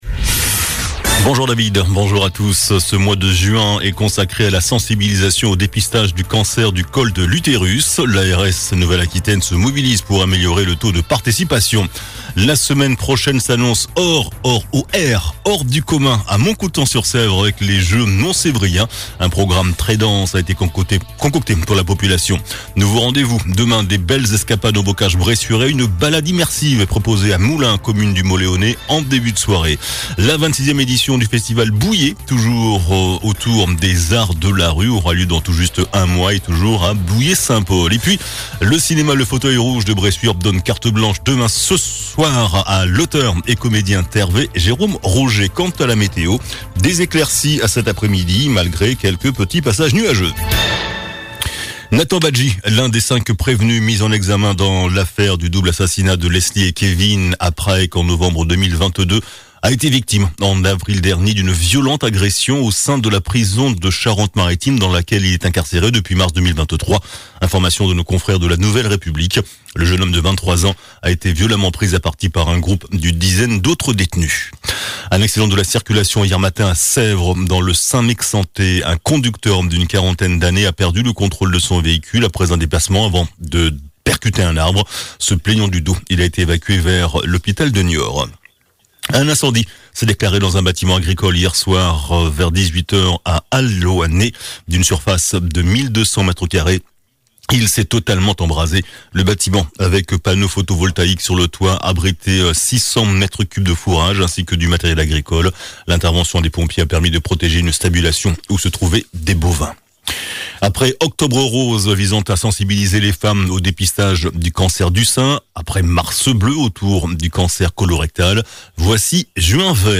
JOURNAL DU JEUDI 06 JUIN ( MIDI )